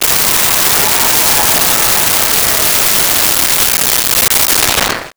Large Crowd Applause 05
Large Crowd Applause 05.wav